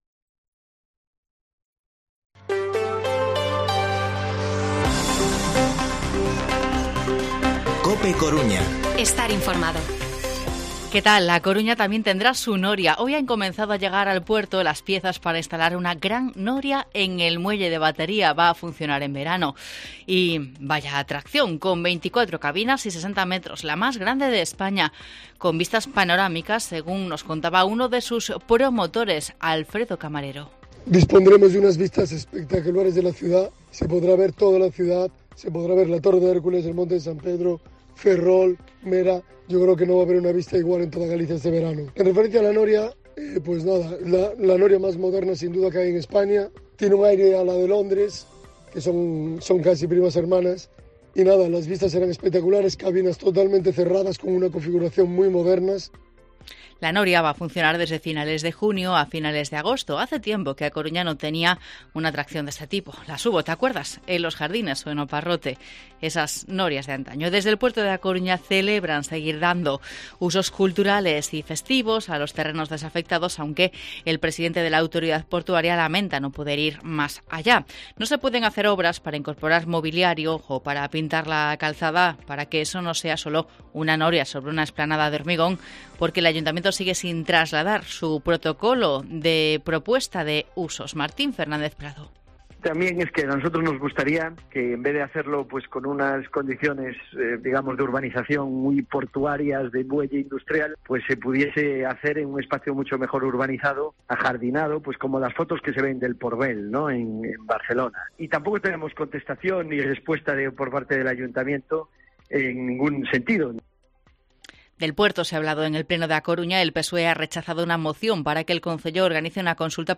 Informativo Mediodía COPE Coruña jueves, 2 de junio de 2022 14:20-14:30